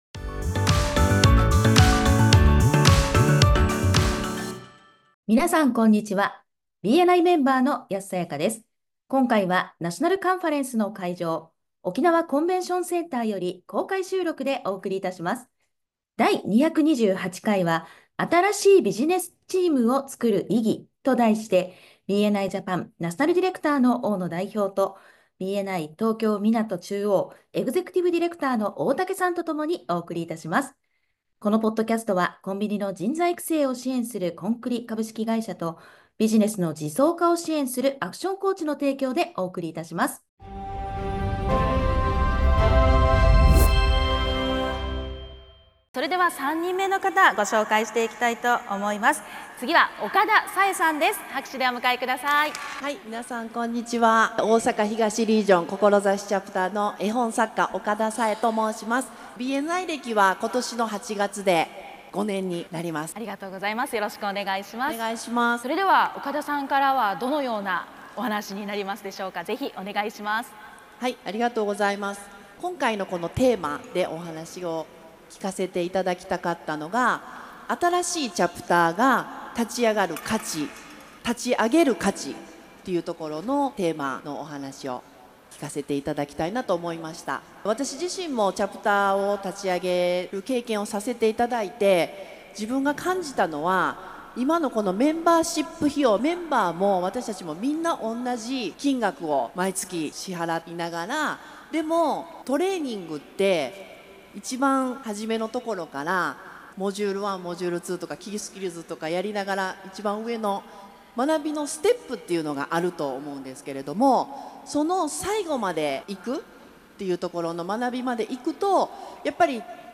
第228回は「ビジター体験を造る」と題してお送りいたします。今回はナショナルカンファレンスの会場、沖縄コンベンションセンターより公開収録でお届けいたします。